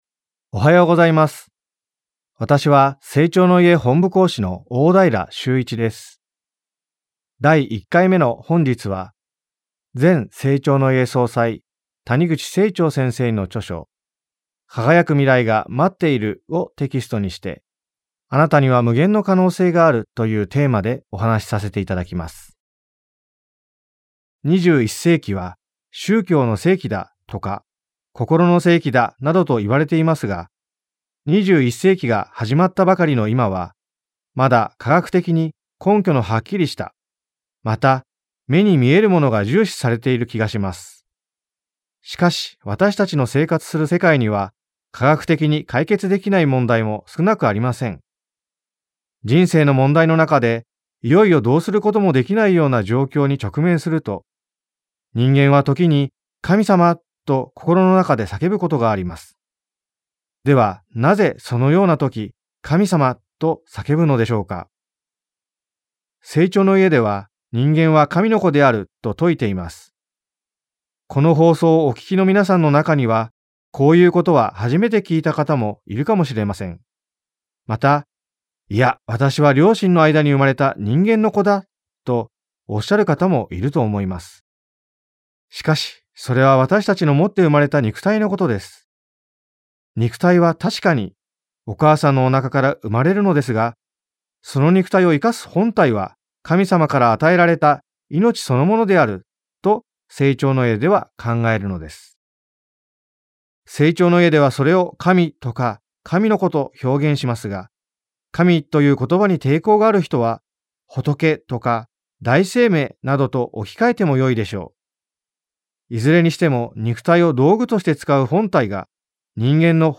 生長の家がお届けするラジオ番組。